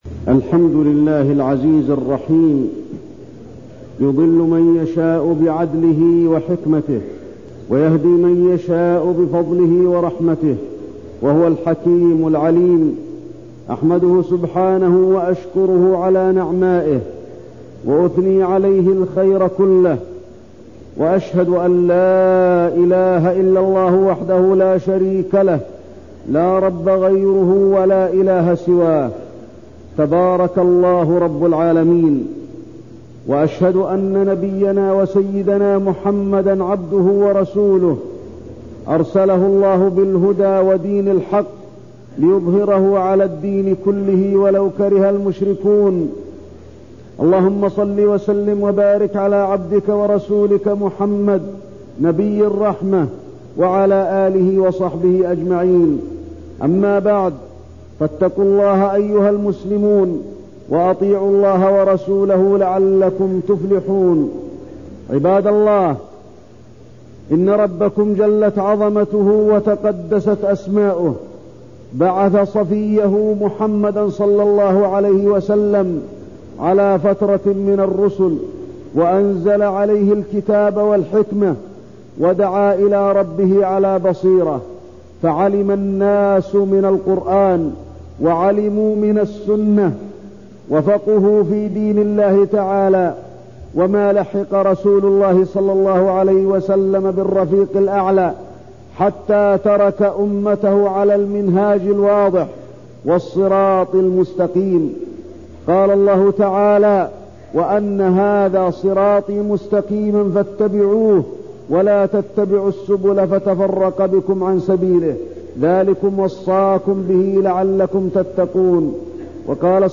تاريخ النشر ٢٨ ذو الحجة ١٤١٣ هـ المكان: المسجد النبوي الشيخ: فضيلة الشيخ د. علي بن عبدالرحمن الحذيفي فضيلة الشيخ د. علي بن عبدالرحمن الحذيفي صفات الفرقة الناجية